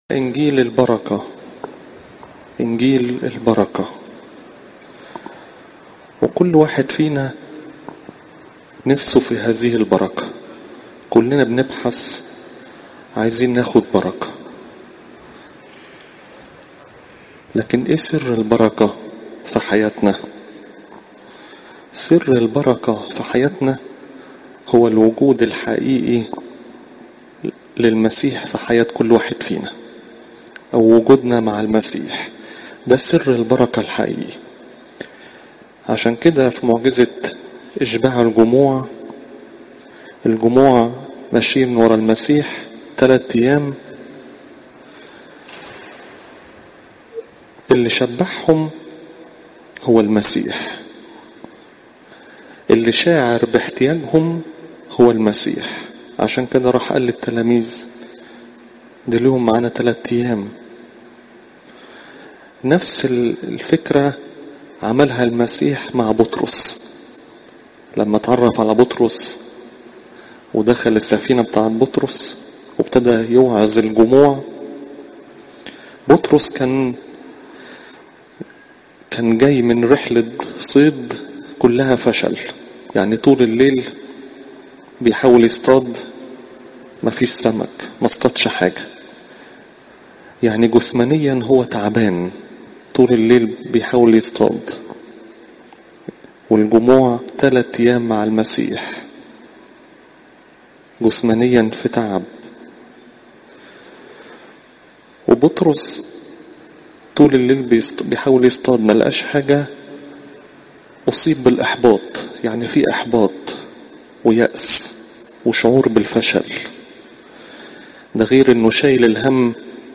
عظات قداسات الكنيسة (لو 9 : 10 - 17) الاحد الثالث من شهر أبيب